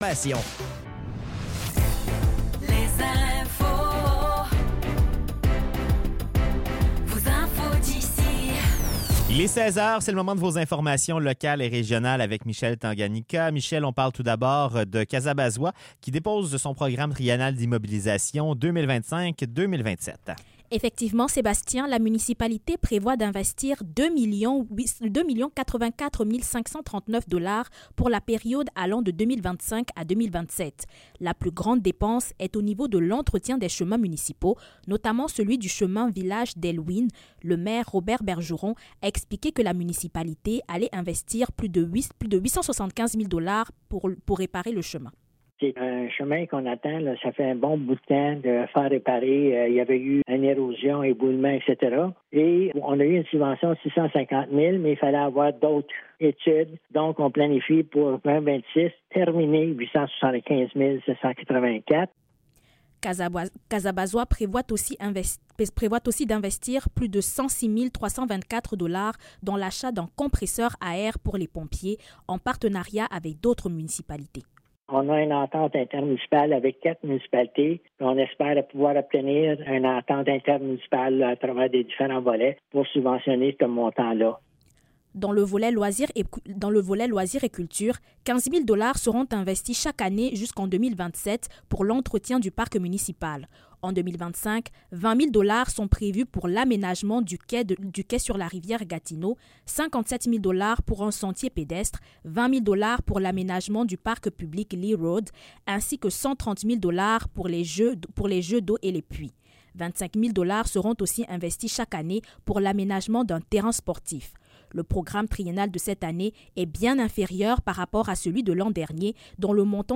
Nouvelles locales - 7 janvier 2025 - 16 h